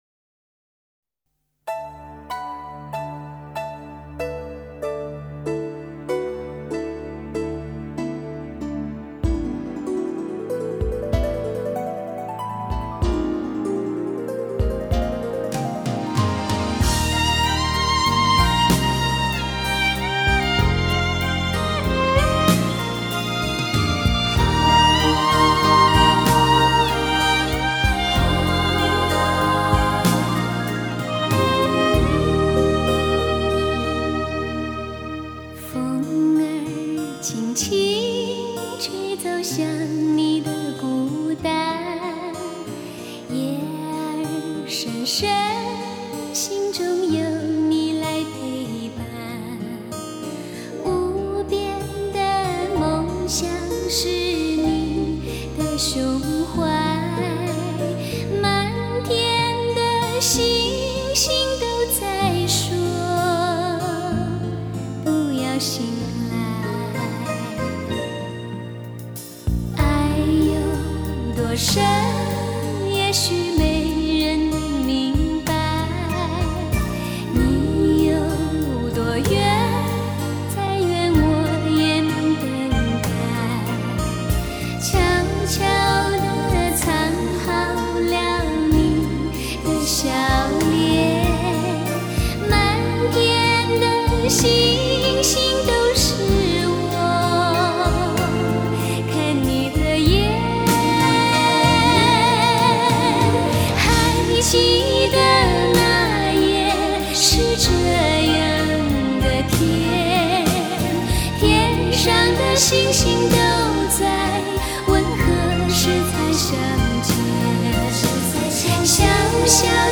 Жанр: Chinese pop